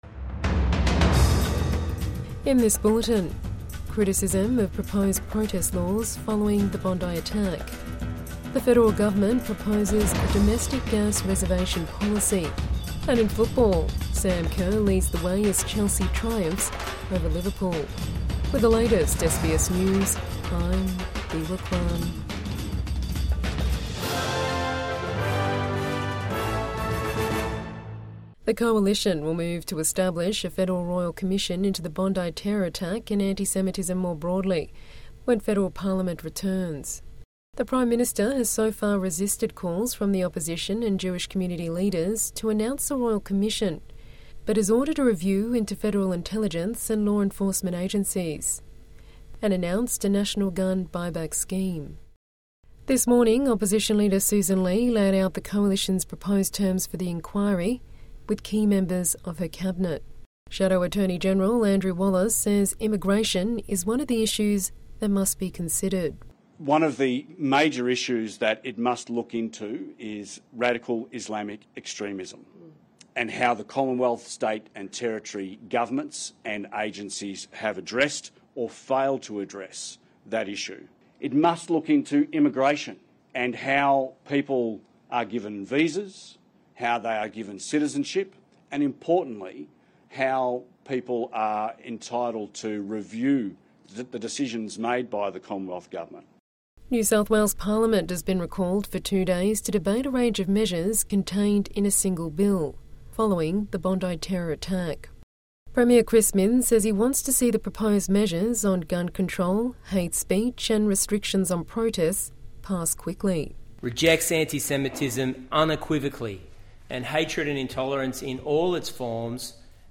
Criticism of proposed protest laws following the Bondi attack | Midday News Bulletin 22 December 2025